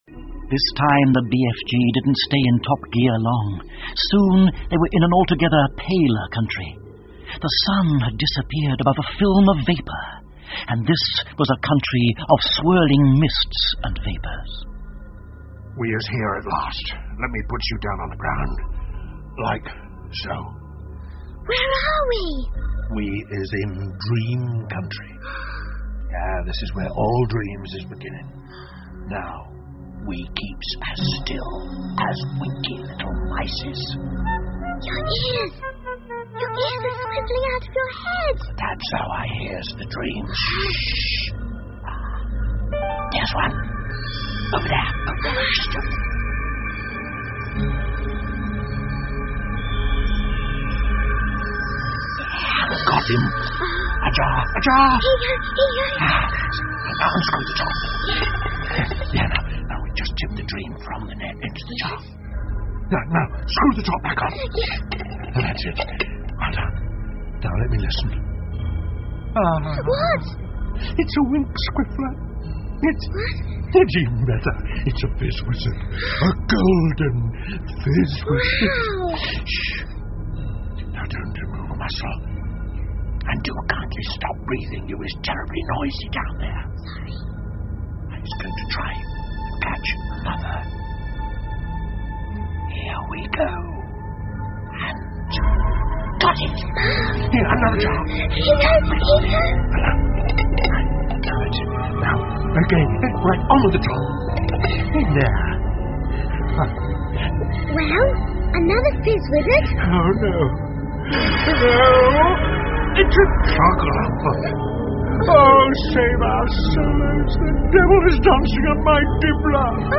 The BFG 好心眼儿巨人 儿童广播剧 9 听力文件下载—在线英语听力室